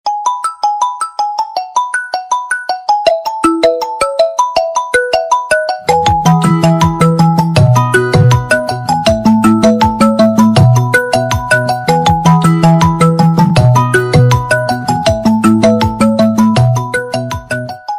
زنگ موبایل برند